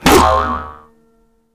box_glove_launch_01.ogg